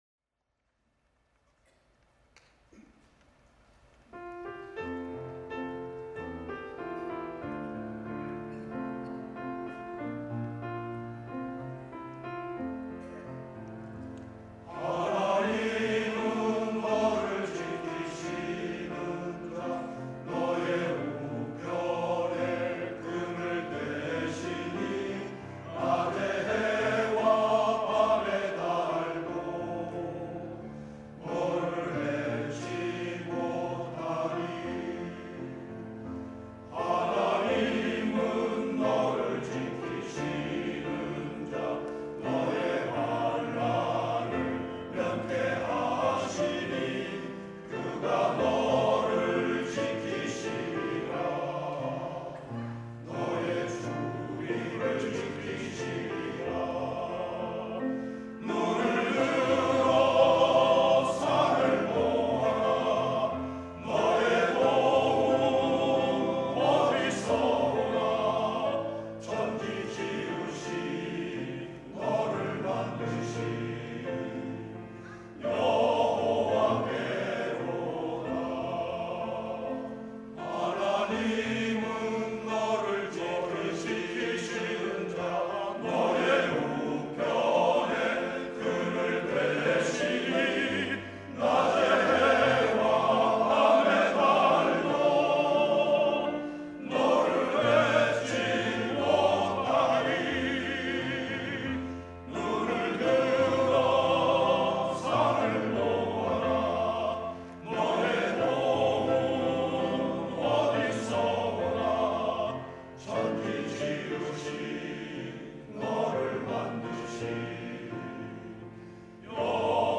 # 첨부 1.01 하나님은 너를 지키시는 자(갈렙남성합창단).mp3